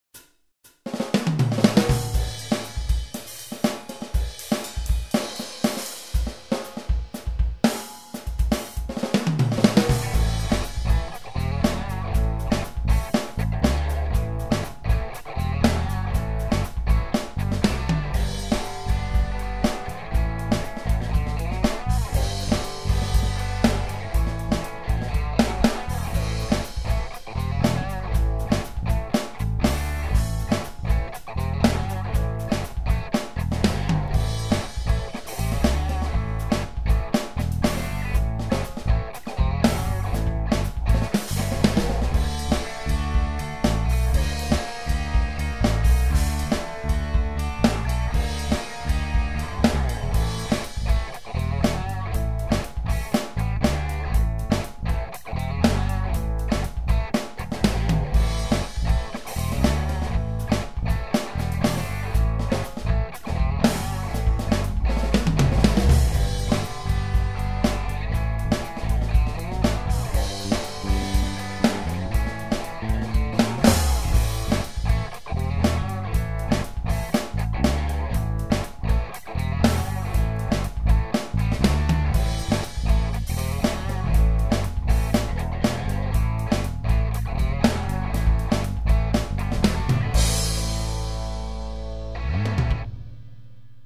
Ce n'est certes pas une batterie mais ce sont des samples de batterie il est donc assez difficile de voir la différence au mixage.
Ah ouais ça a l'air pas mal du tout, même les nuances dans le jeu sont bien respectées !